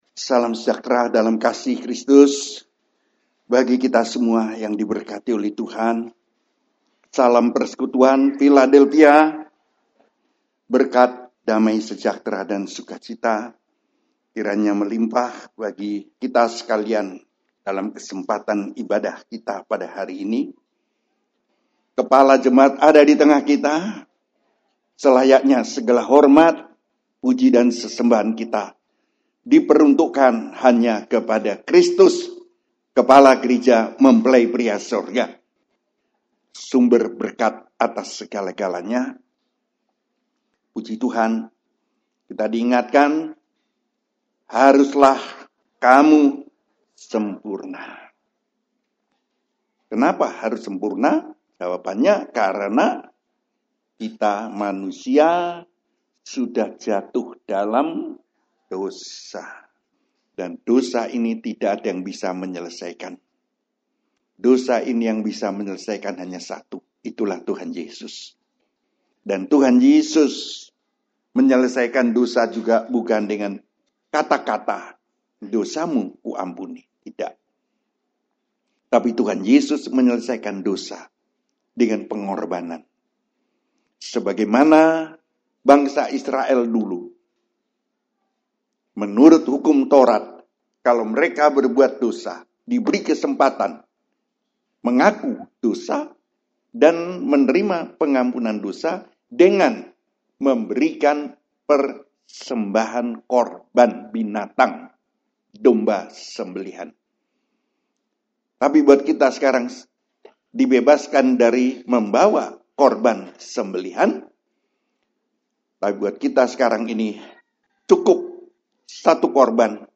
Khotbah Pengajaran